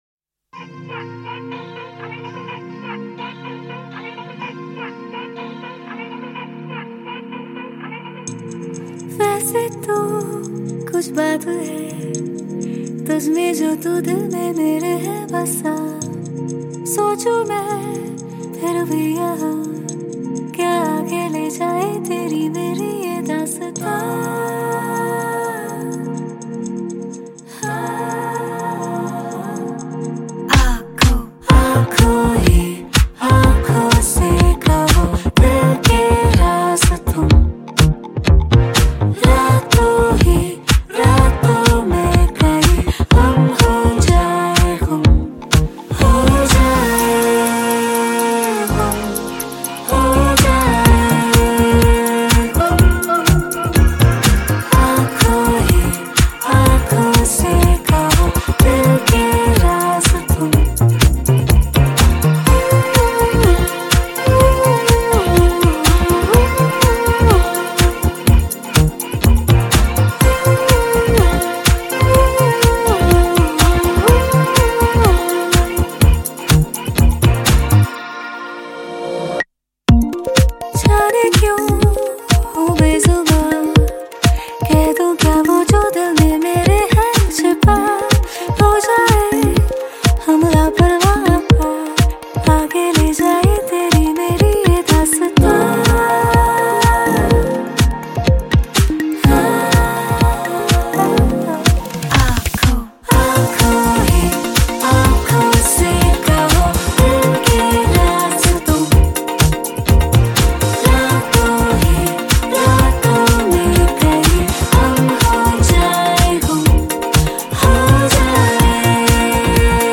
Hindi